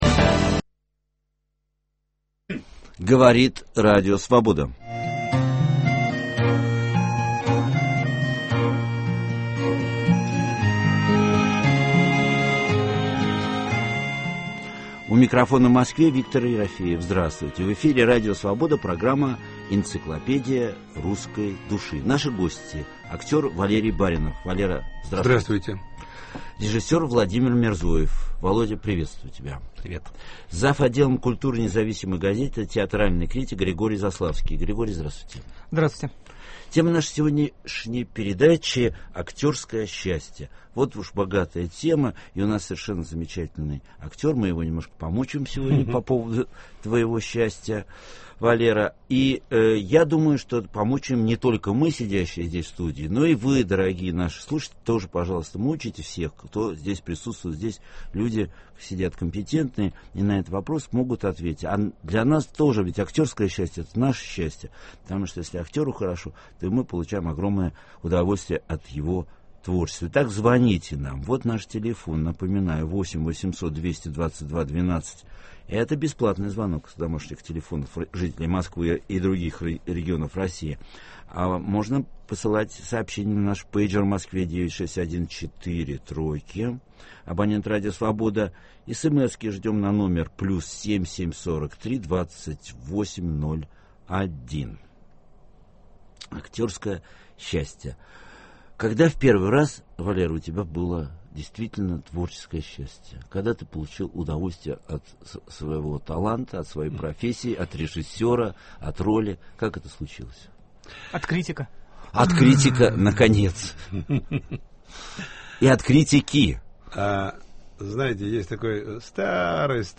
В прямом эфире мы поговорим на тему - "Актерское счастье".